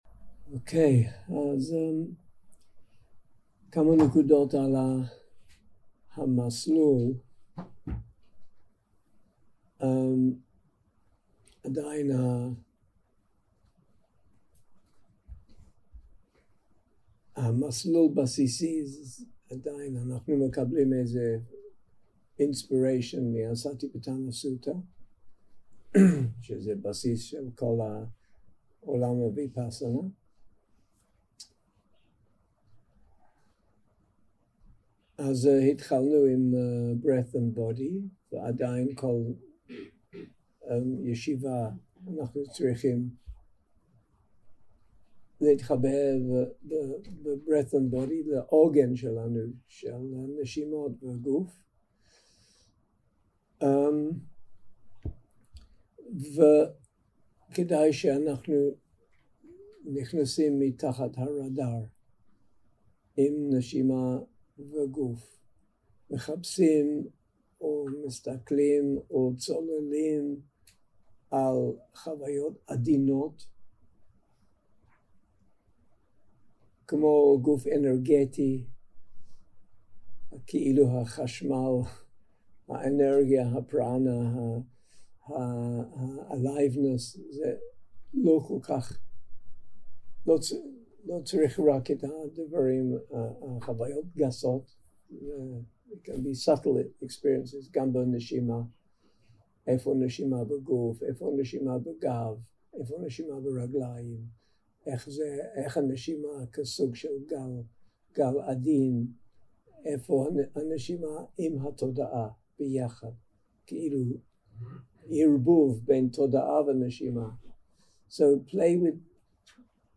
יום 5 - הקלטה 10 - בוקר - הנחיות למדיטציה - מפת תרגול, וסימנים לפחות selfing 0:00 0:00 סוג ההקלטה: סוג ההקלטה: שיחת הנחיות למדיטציה שפת ההקלטה: שפת ההקלטה: אנגלית